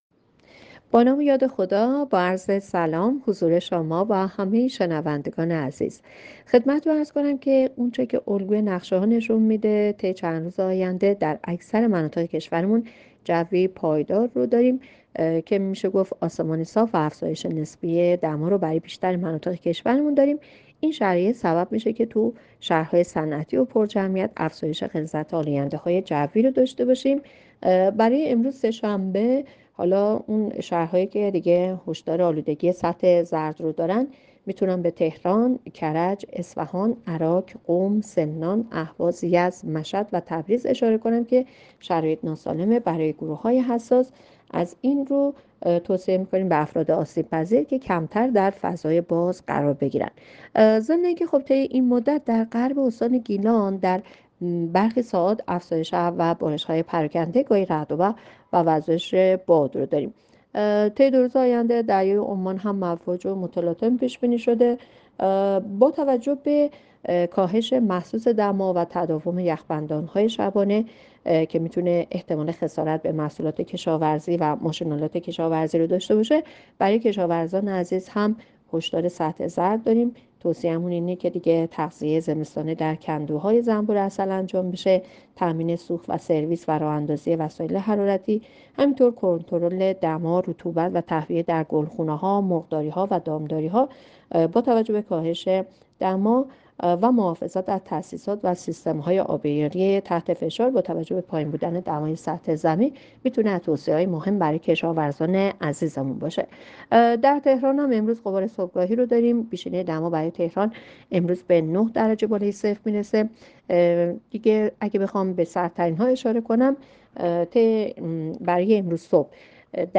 گزارش رادیو اینترنتی پایگاه‌ خبری از آخرین وضعیت آب‌وهوای ۱۳ آذر؛